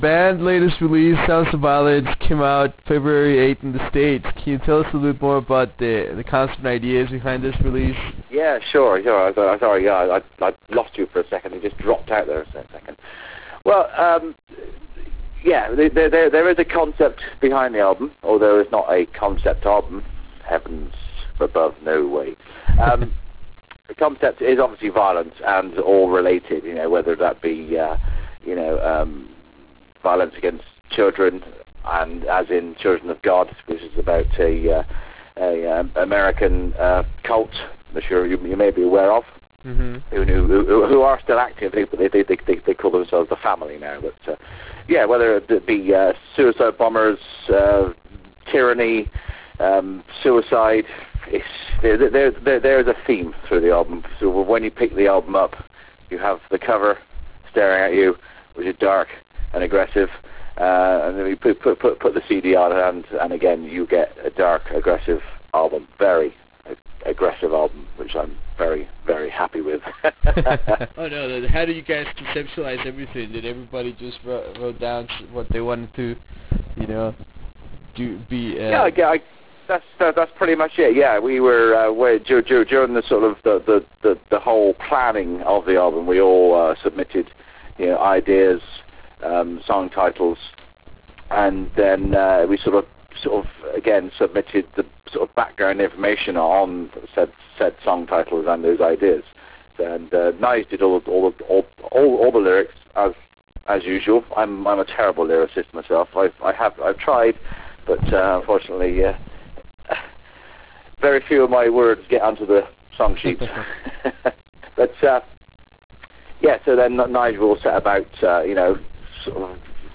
In this interview we talk about the writing process of this album and what it feels to be back in the spotlight after a very long break. We also discuss the band’s future plans and touring schedule, which will include North American dates.